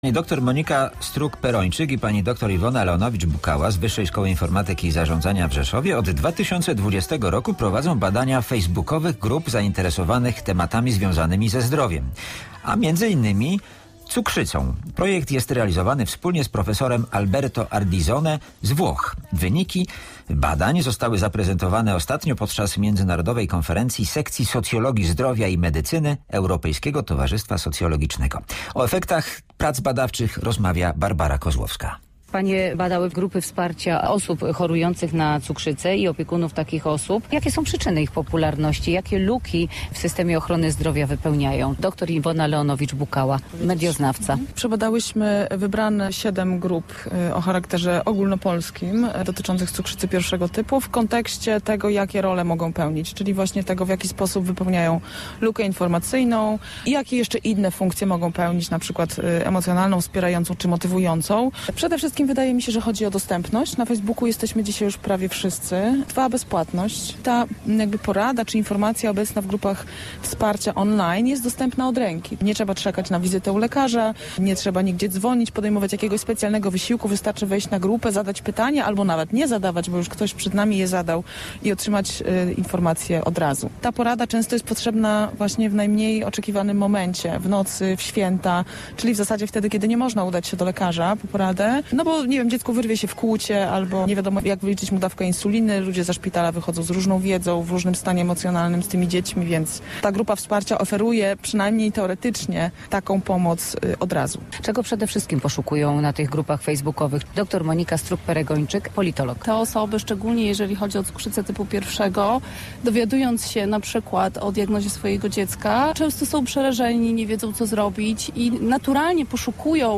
O problematyce facebookowych grup online dla chorych na cukrzycę – rozmowa